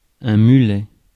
Ääntäminen
Synonyymit mule meuille muge rouget-barbet mulet doré Ääntäminen France: IPA: [my.lɛ] Haettu sana löytyi näillä lähdekielillä: ranska Käännös Substantiivit 1. кефал Suku: m .